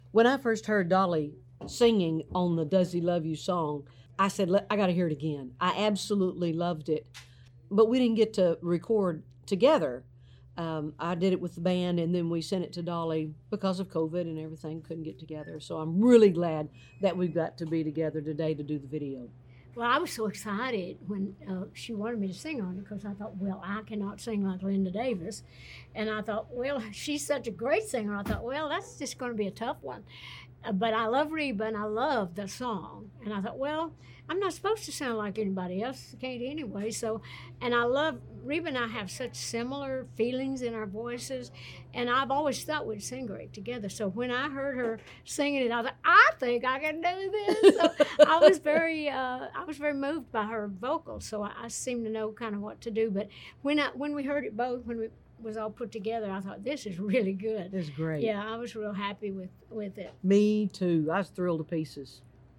Audio / On the set of the “Does He Love You” video, Reba McEntire and Dolly Parton talked about recording the new version of it for Reba’s REVISED REMIXED REVISITED box set.